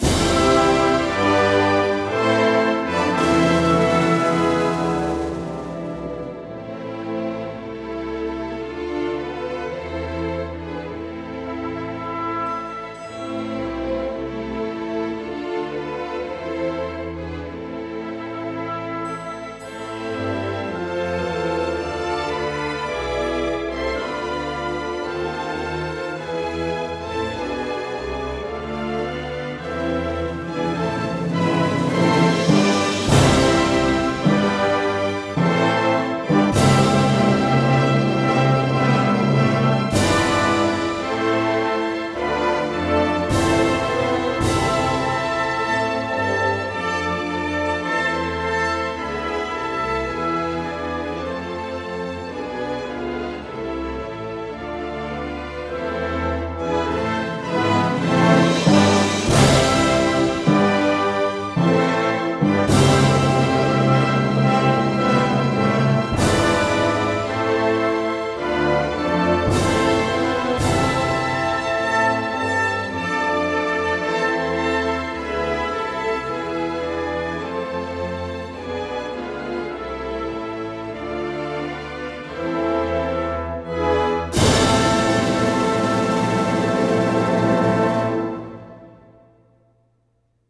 Маестро Найден Тодоров и Софийската филхармония направиха нови дигитални записи на двата химна в края на април.
Новите записи са направени в зала „България“ и освен че притежават висока художествена стойност, отговарят и на технически изисквания на новото време.
Инструментал - България